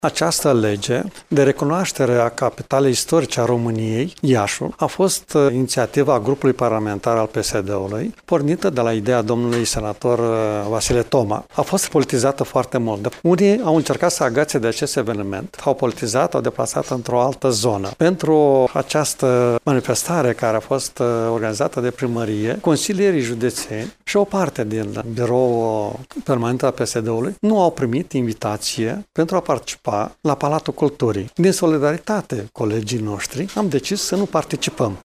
Liderul PSD Iaşi, Maricel Popa, a declarat că iniţiativa legislativă privind conferirea acestui titlu aparţine social democraţilor.